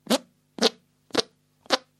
Звук подмышечного пердежа